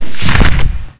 smack.wav